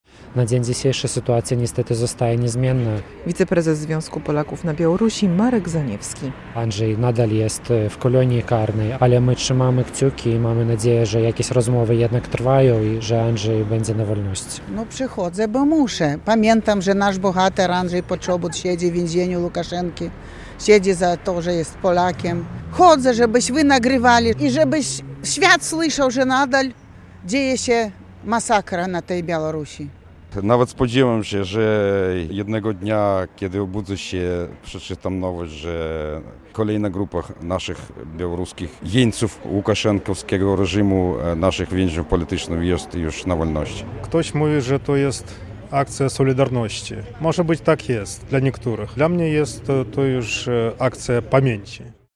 Wciąż mamy nadzieję, że Andrzej Poczobut wkrótce odzyska wolność - powtarzali uczestnicy akcji solidarności z uwięzionym w białoruskim więzieniu dziennikarzem. W centrum Białegostoku, jak co miesiąc, odbyła się w czwartek (25.09) akcja w jego obronie.